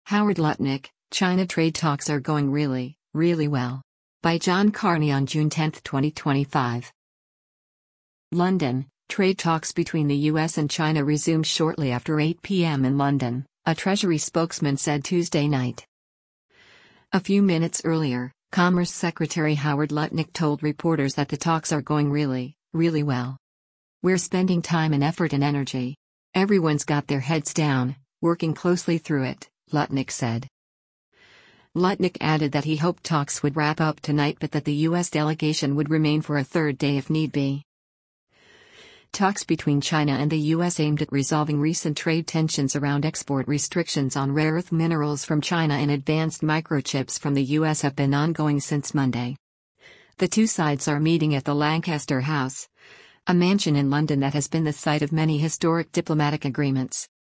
U.S. Commerce Secretary Howard Lutnick speaks with the press after a session of U.S.-China